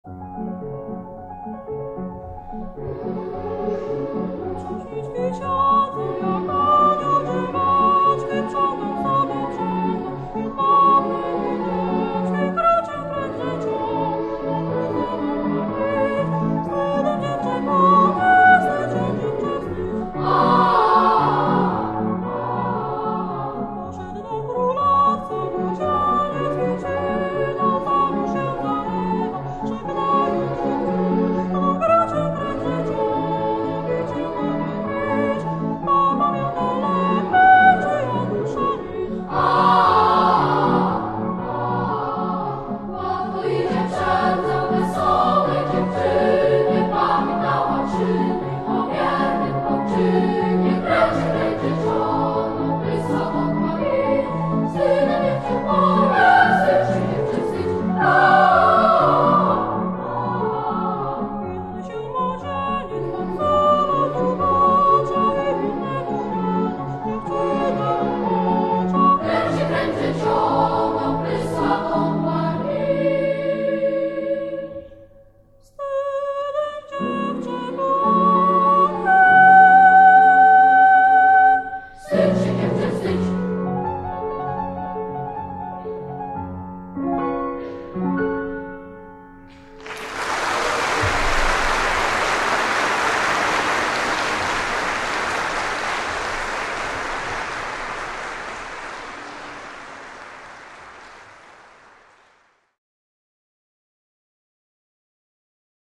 wykonuje chór ch³opiêcy